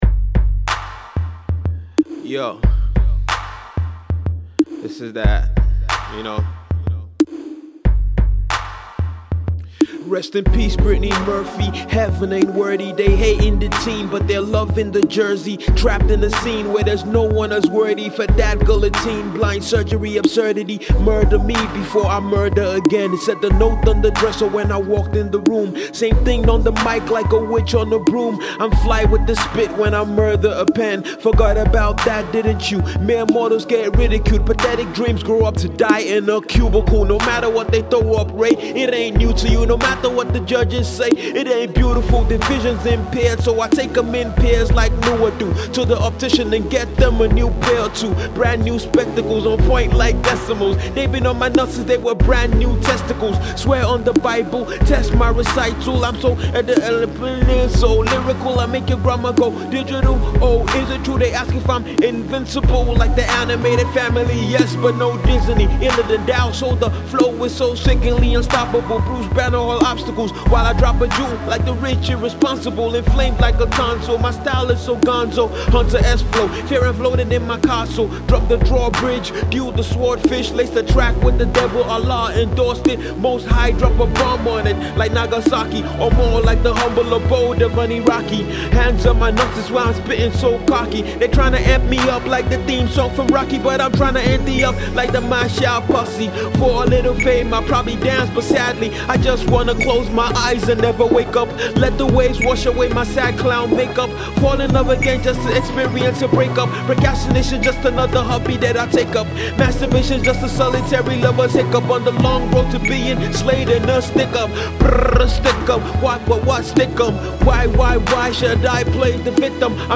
Note*recommended for Hip-Hop Heads…